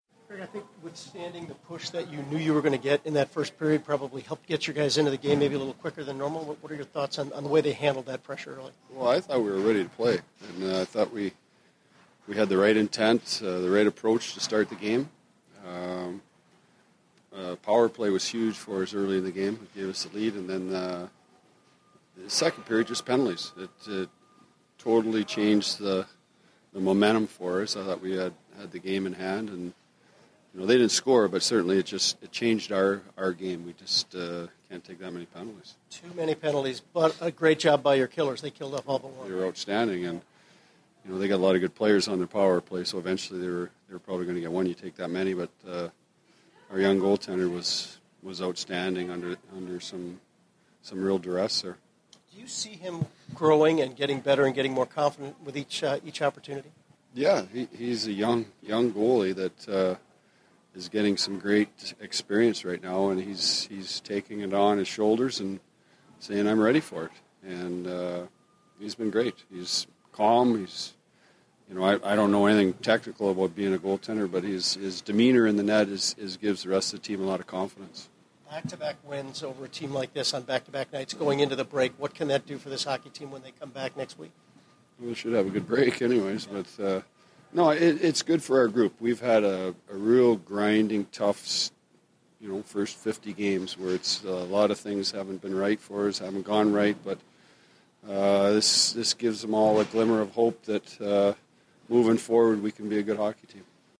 Craig Hartsburg Post-Game 01/26/16